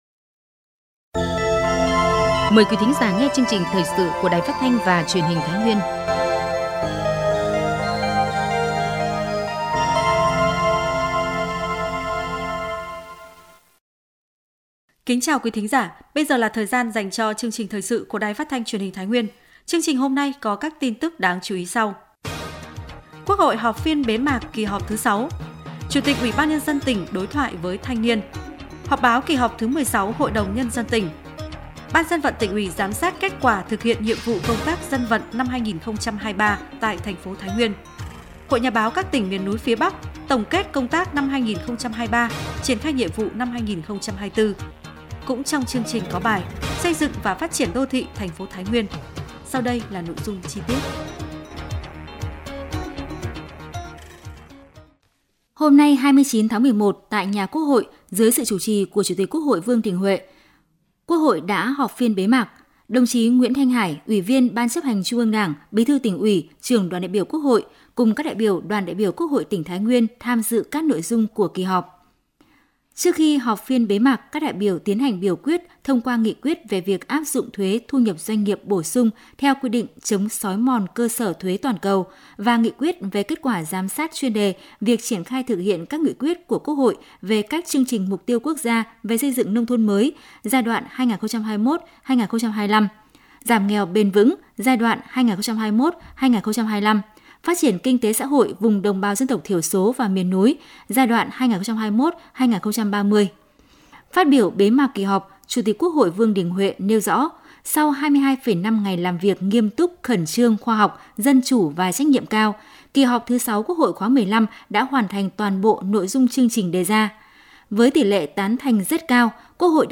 Thời sự tổng hợp Thái Nguyên ngày 02/12/2023